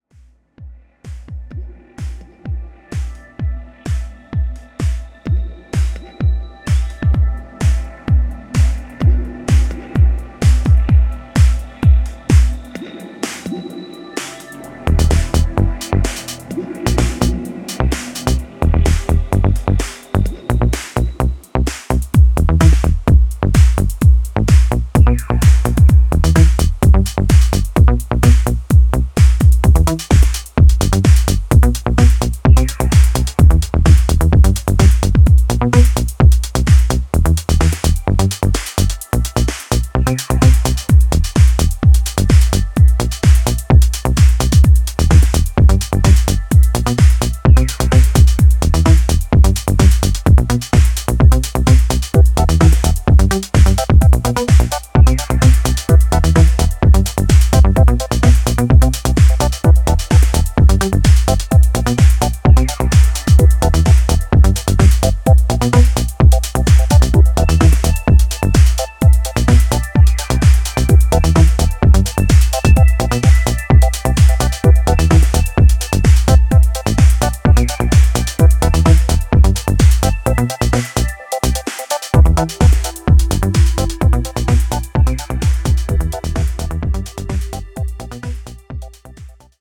sultry sounds